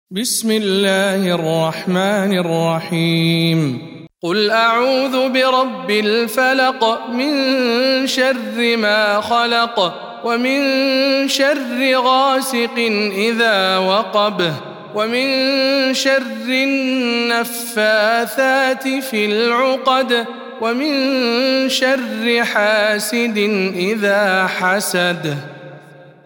سورة الفلق - رواية هشام عن ابن عامر